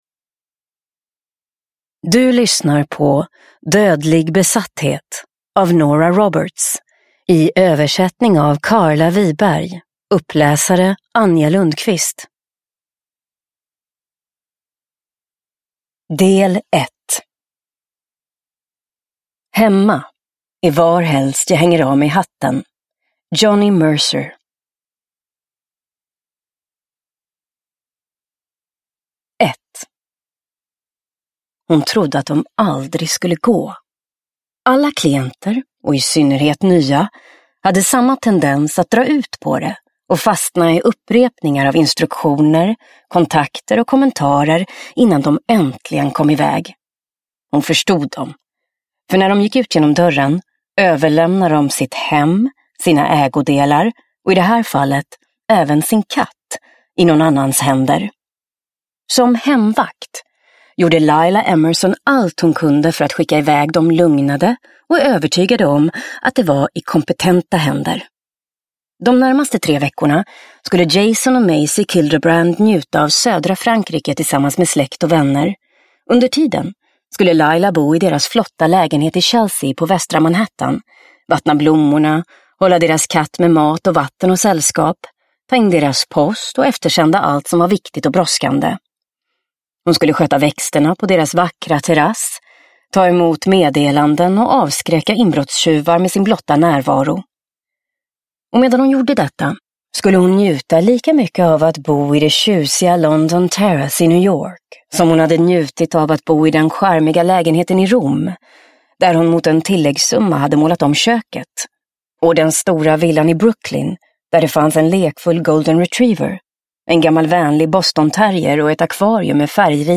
Dödlig besatthet – Ljudbok – Laddas ner